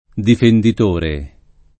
difenditore [ difendit 1 re ]